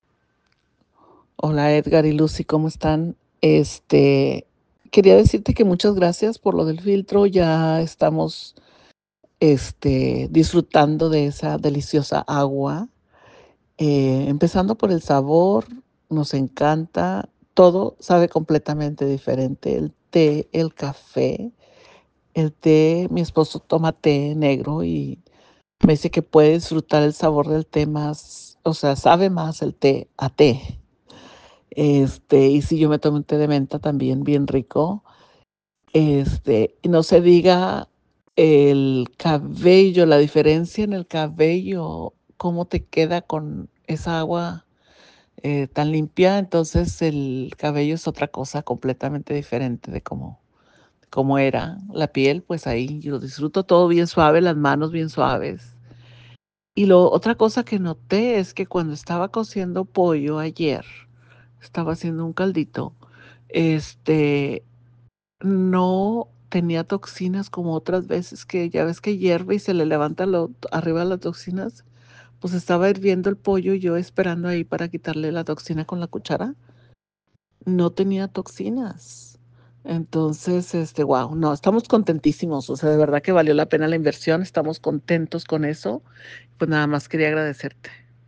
Un cliente más disfrutando de agua pura y de calidad en su hogar gracias a nuestro sistema de filtración para toda la casa. ¡Escucha su experiencia y descubre cómo Aquality Systems transforma el agua y la vida!
testimonio.mp3